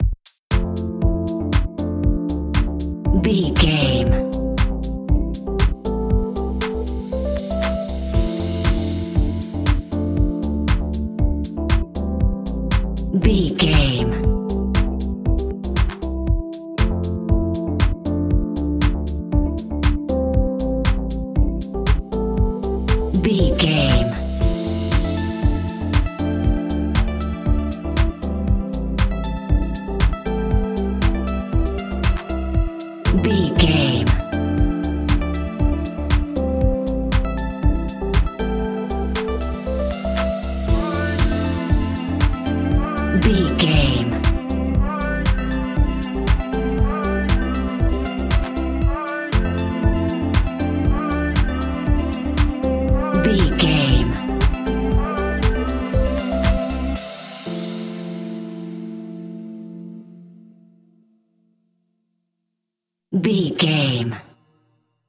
Ionian/Major
groovy
uplifting
driving
energetic
bouncy
electric piano
synthesiser
drum machine
vocals
electronic
synth bass